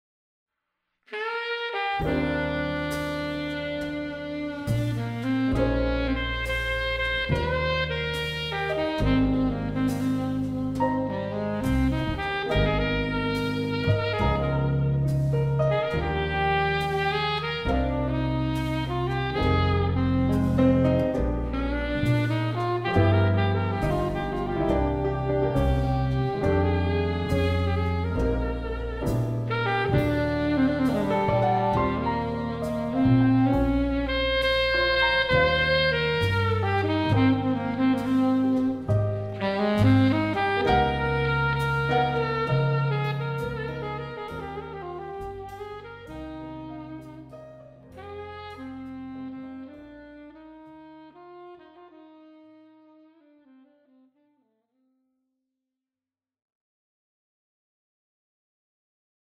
SAX & VOICE